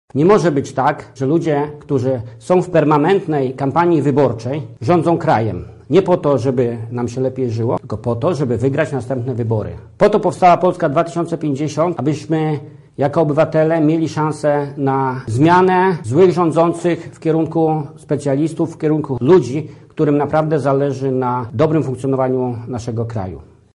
Uważamy, że trzeba zmienić te rządy, jak ja to mówię „patałachów” – zaznacza senator Jacek Bury, jeden z wojewódzkich pełnomocników formacji Hołowni: